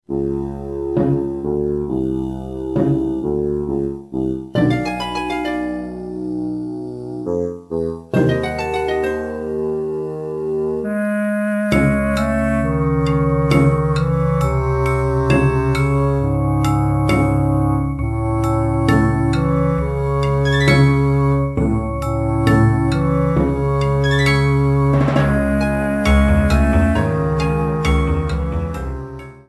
Ripped from the remake's files